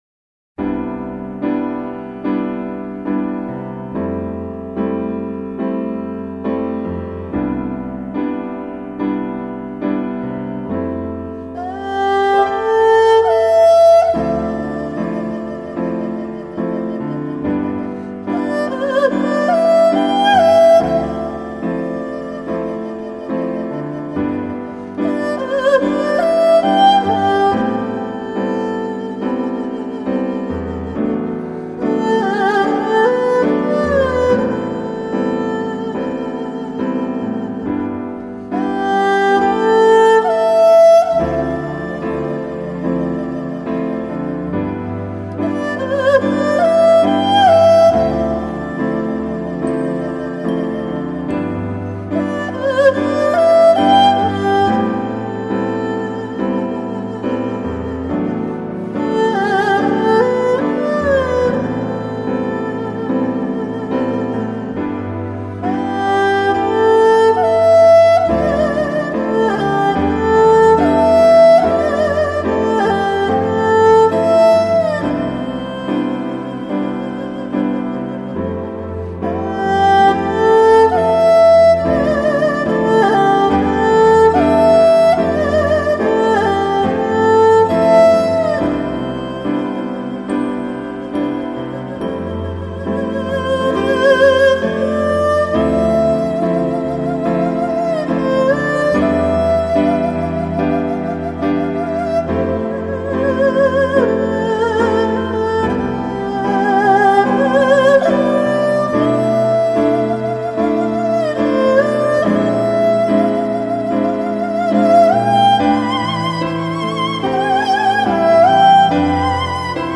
当你听收录的这十首曲子时，东方与西方的音乐元素被美妙地揉合起来，你不由自主的被拉进音声的世界。
这舒缓的钢琴和东方乐器中流动着的音符使我们想到这些。